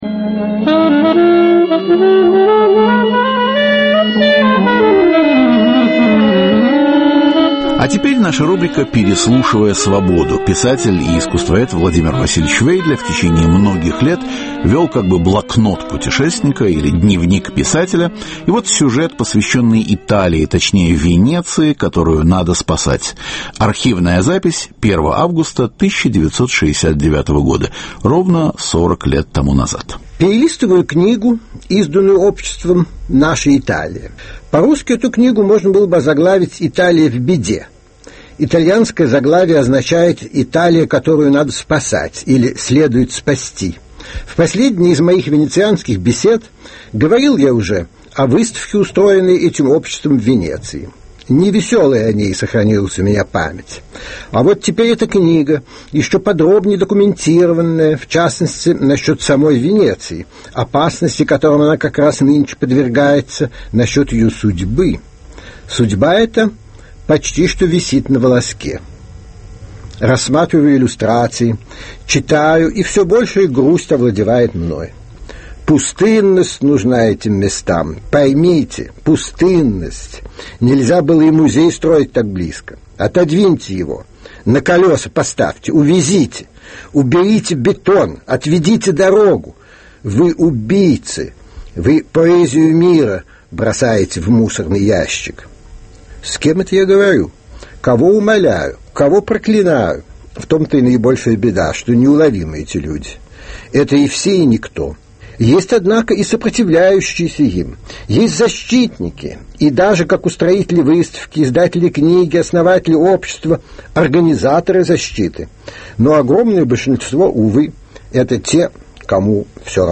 Переслушивая Свободу – сохранение памятников искусства в Италии, из блокнота путешественника Владимира Вейдле, запись 1969 года.